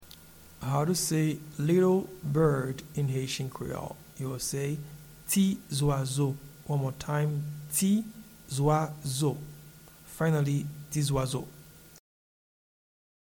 Pronunciation and Transcript:
Little-bird-in-Haitian-Creole-Ti-zwazo.mp3